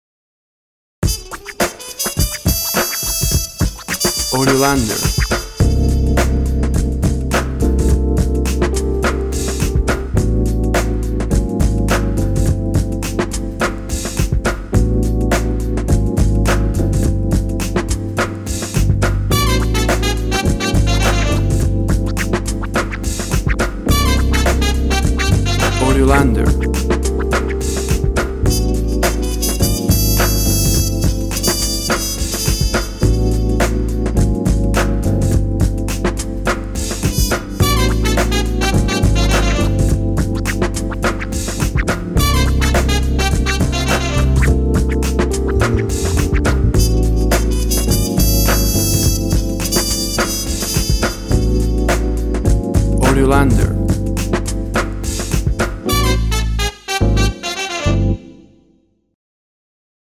Urban jazz acid sounds with Brass section.
Tempo (BPM) 105